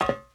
metalFootStep02.wav